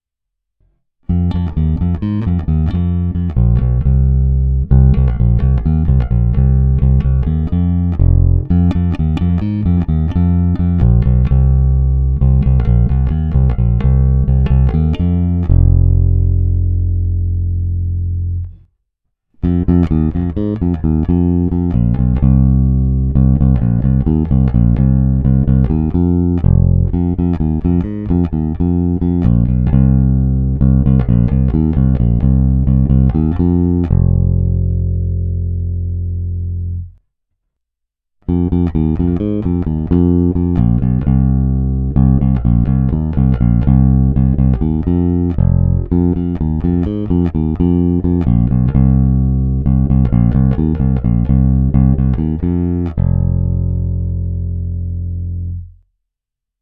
Neskutečně pevný, zvonivý, s těmi správnými středy, co tmelí kapelní zvuk, ale při kterých se basa i prosadí.
Není-li uvedeno jinak, následující nahrávky jsou provedeny rovnou do zvukové karty, jen normalizovány, jinak ponechány bez úprav.